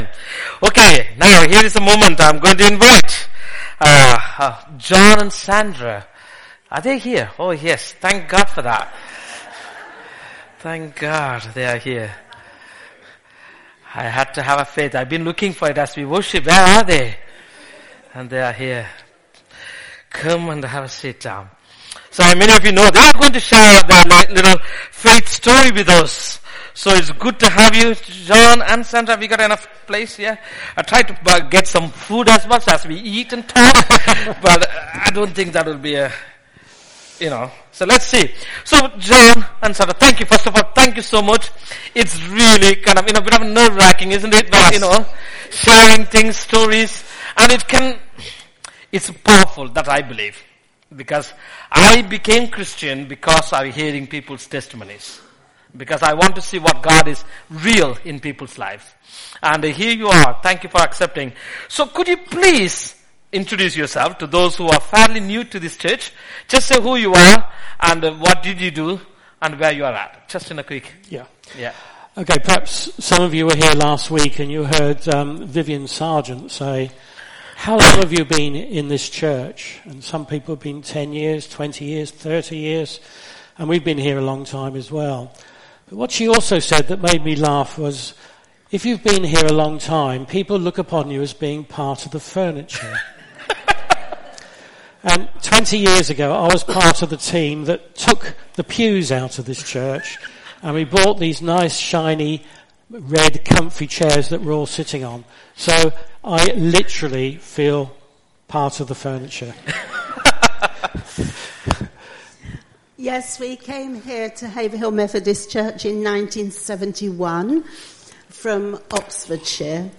An audio version of the service (without songs) is also available.
Service Type: All Age Worship